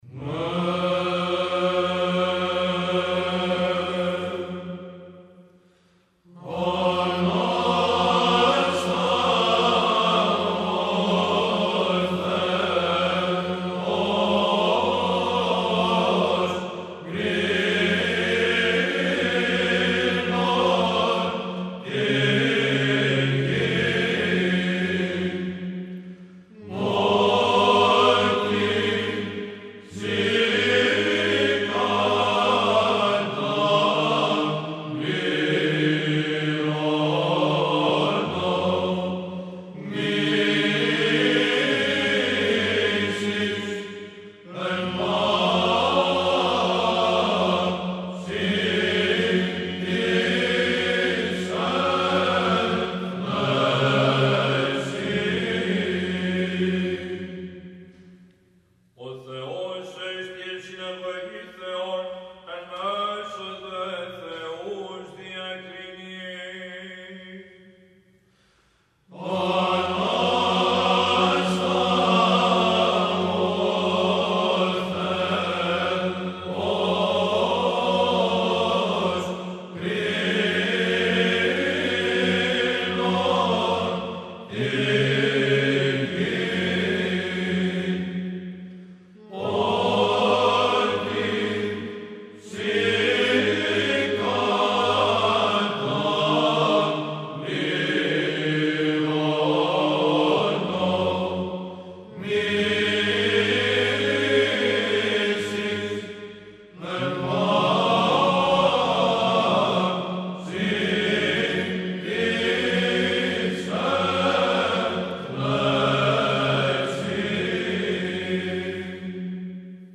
Ακούστε το από την Βυζαντινή χορωδία του Συλλόγου Μουσικοφίλων Κωνσταντινουπολιτών.